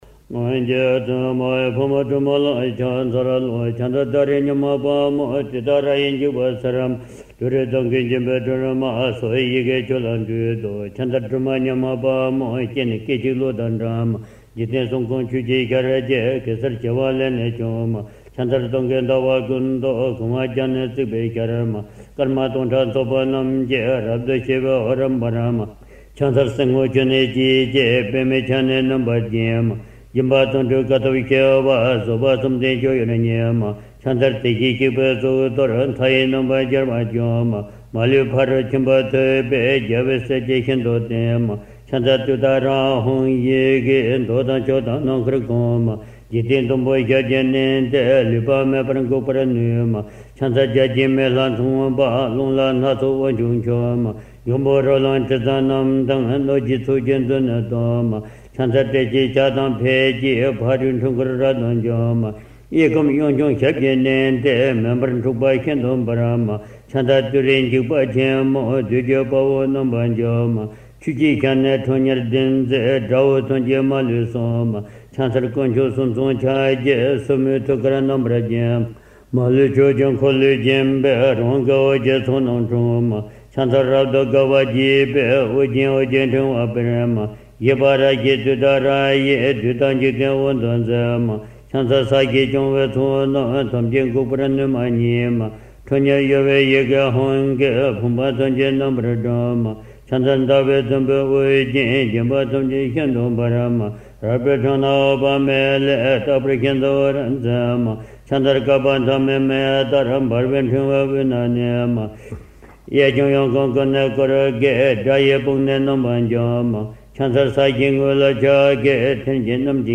Enregistrer à Montchardon lors de la récitation du matin
Audio uniquement de la louange qui se répète en boucle